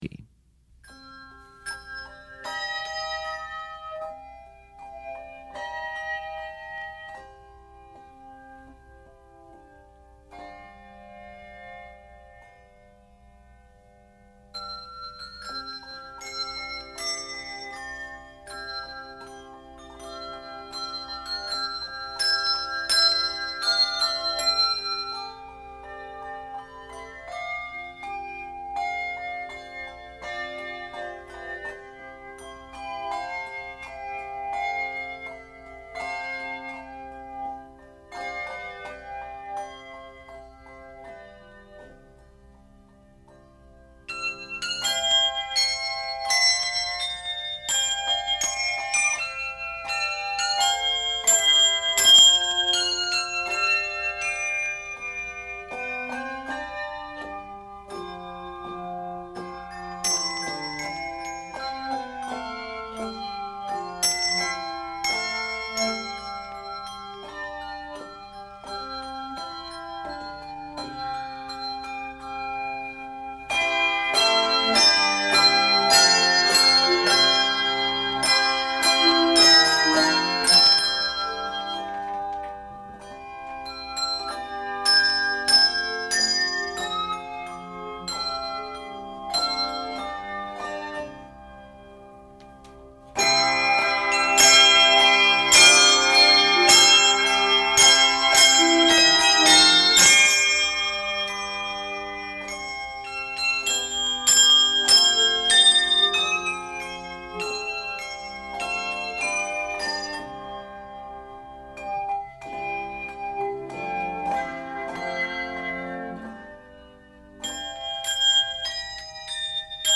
Very chordal with a big ending.